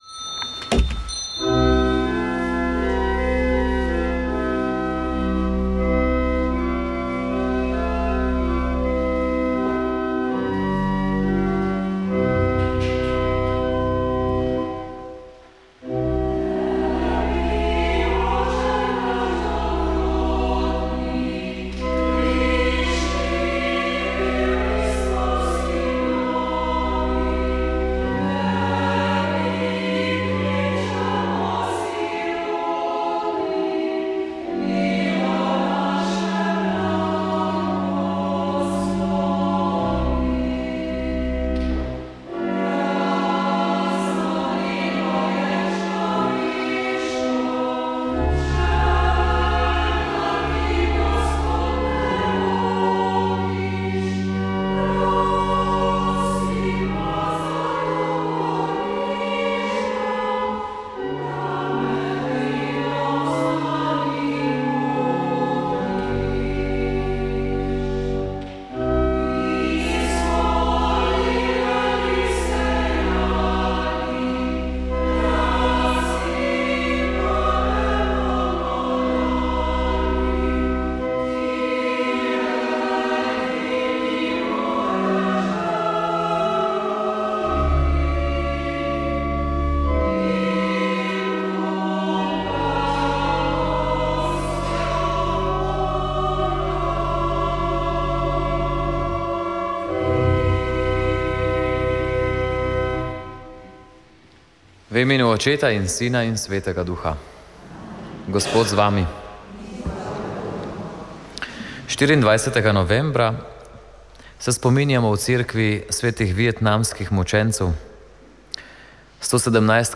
Sveta maša
Sv. maša iz župnijske cerkve sv. Jožefa in sv. Barbare iz Idrije 24. 11.